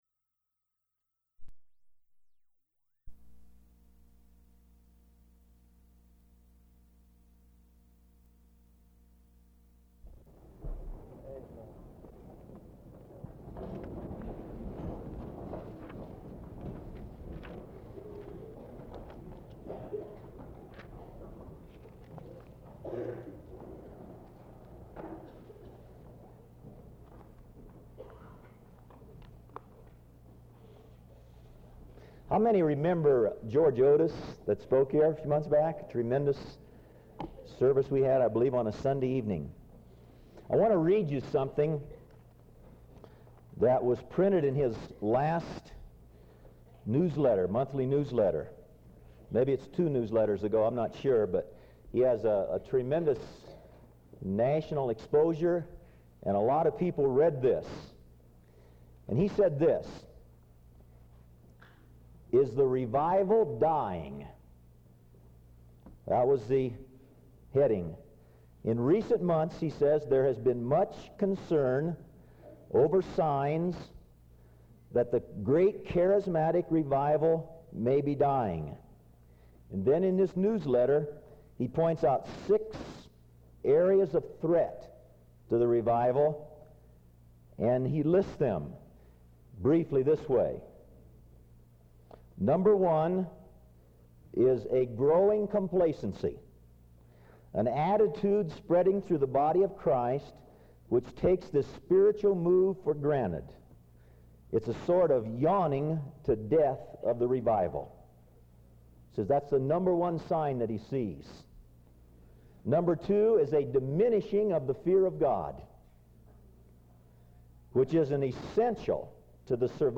A message from the series "Sunday Sermons."